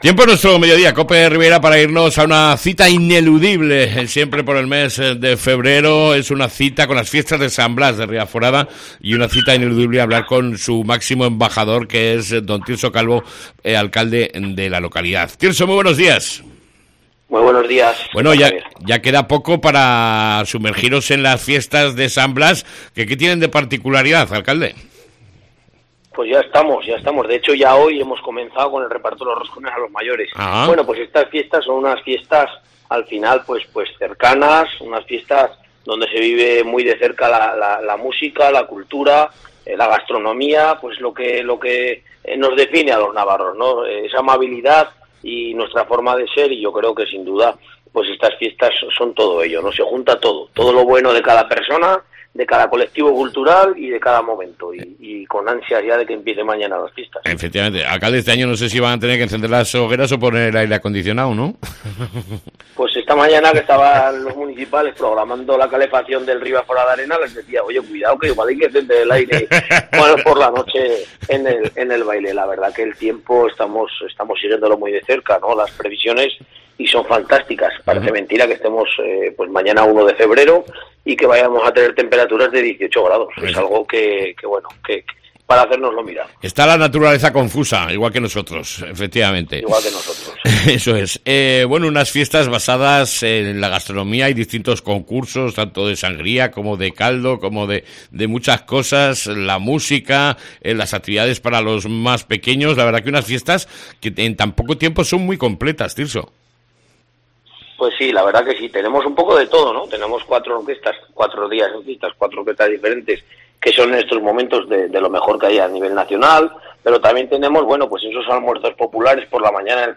ENTREVISTA CON EL ALCALDE DE RIBAFORADA, TIRSO CALVO